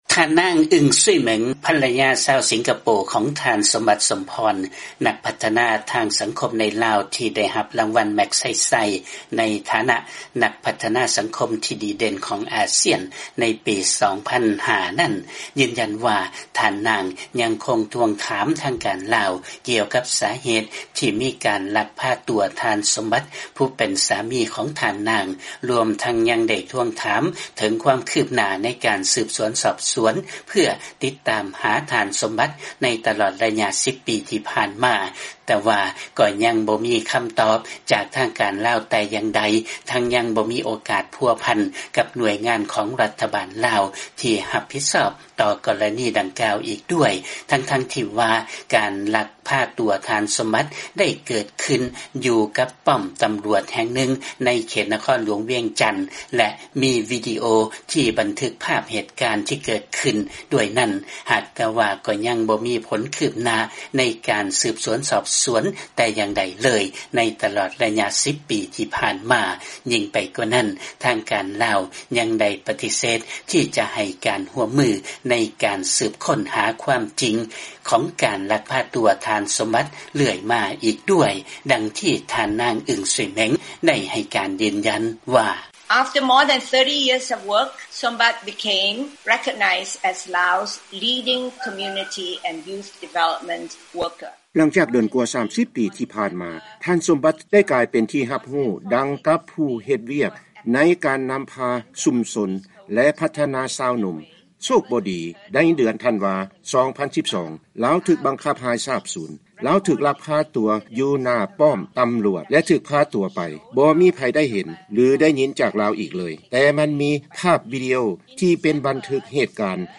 ຟັງລາຍງານ ໄລຍະ 10 ປີທີ່ຜ່ານມາຍັງຄົງບໍ່ມີຄຳຕອບ ແລະ ການອະທິບາຍໃດໆຈາກລັດຖະບານ ລາວ ທີ່ກຽວກັບສາເຫດເບື້ອງຫຼັງທີ່ເຮັດໃຫ້ມີການລັກພາຕົວທ່ານ ສົມບັດ